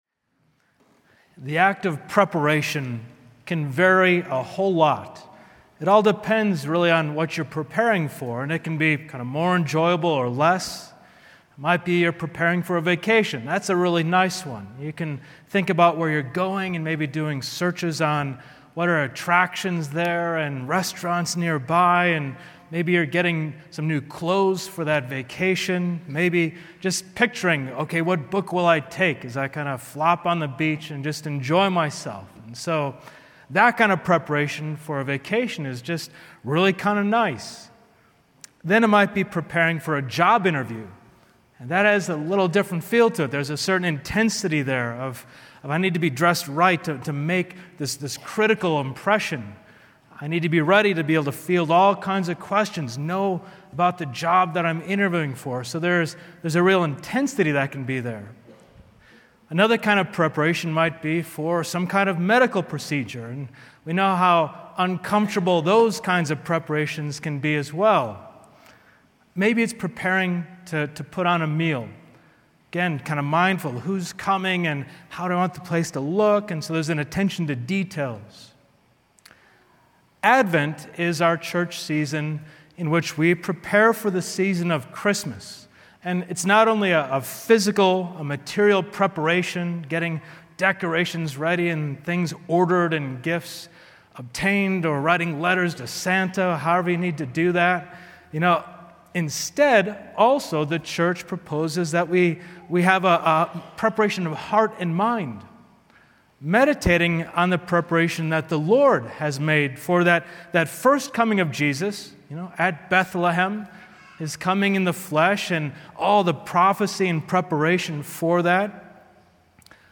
Homilies